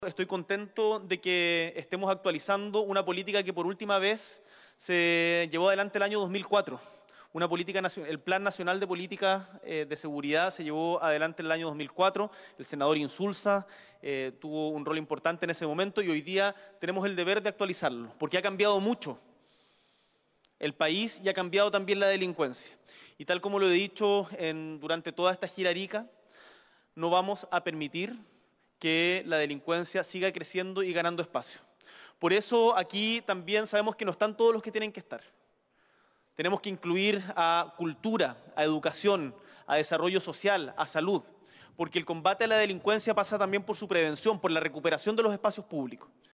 “Tenemos el deber de actualizarlo porque ha cambiado el país y también la delincuencia”, sostuvo tras el encuentro, en un punto de prensa.